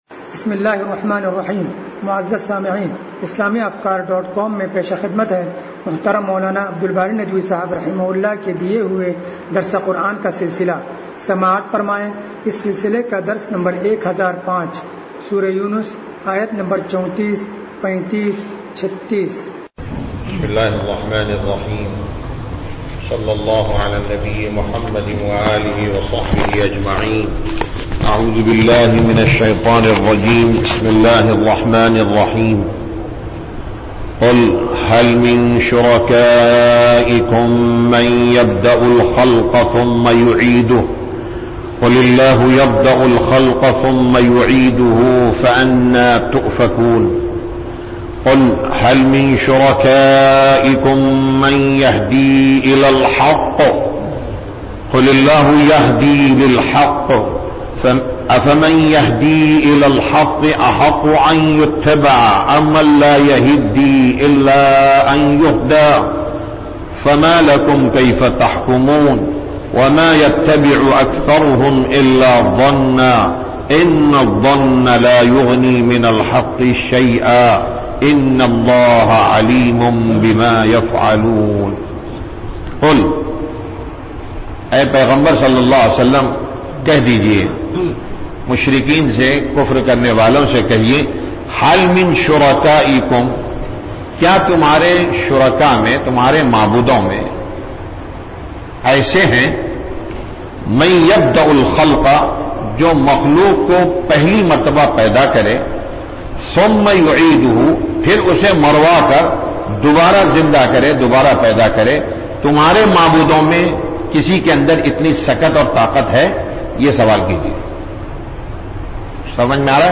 درس قرآن نمبر 1005